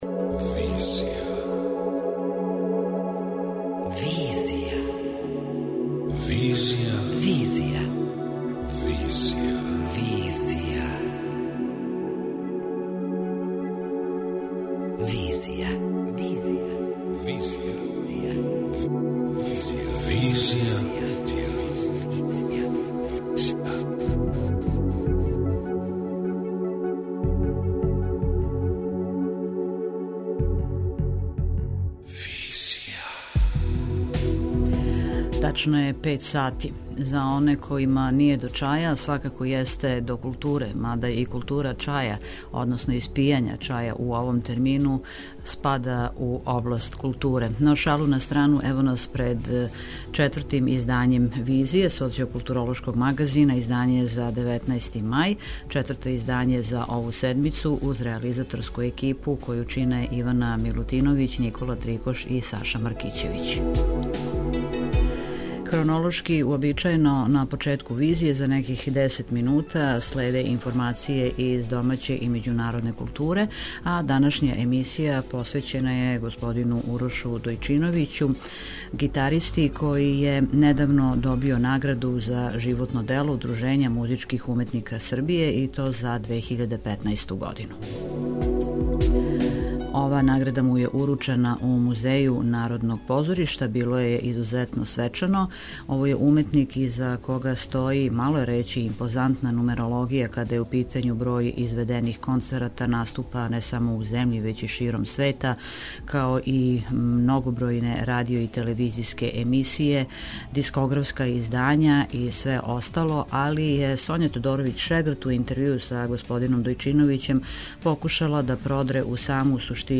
интервјуу